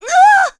Laudia-Vox_Damage_kr_03.wav